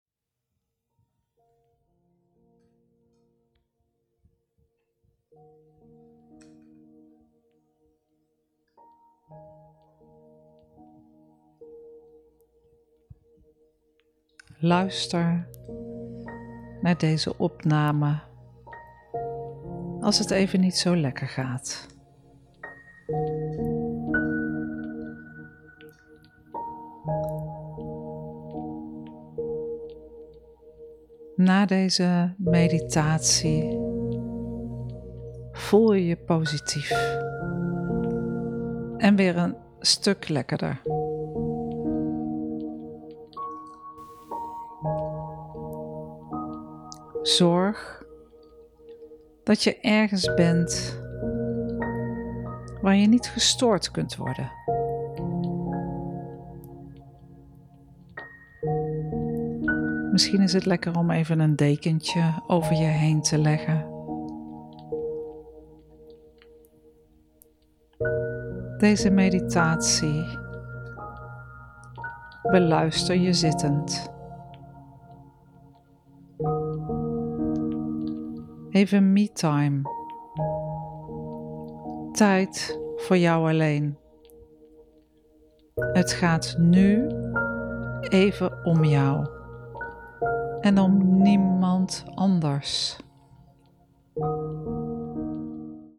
Help! als je het even niet meer weet meditatie: (18 minuten)